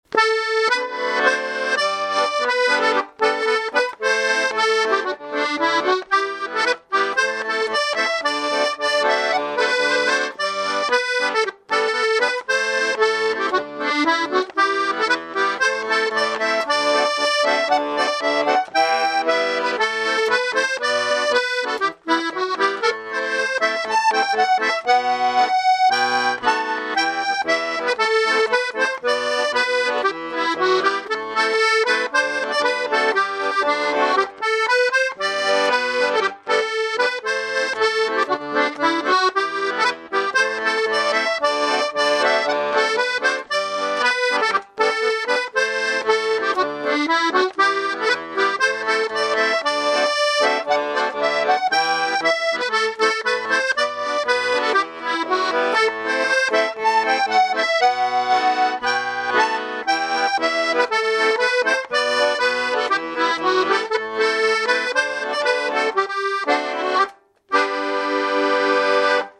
walking tune